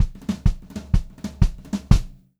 126CLFILL1-L.wav